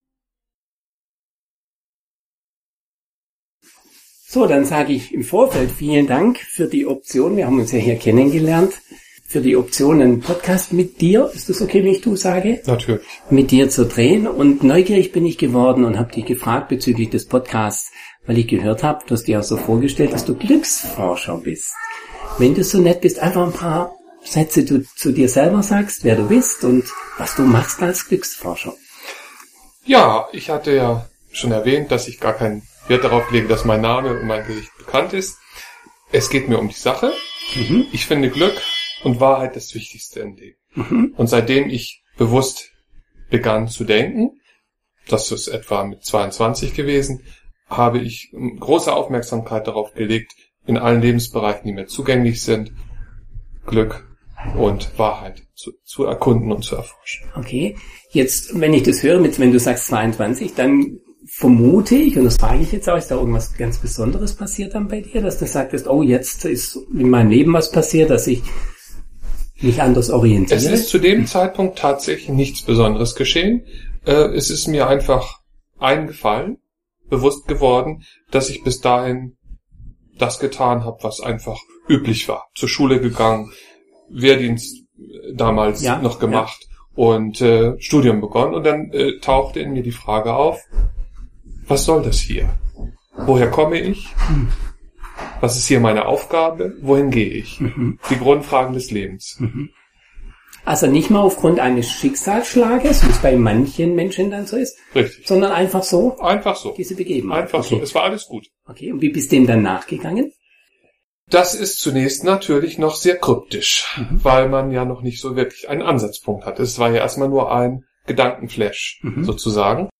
In diesem Interview bin ich im Gespräch mit einem Glücksforscher.
In der Hotellobby. Das hört man auch ab und zu.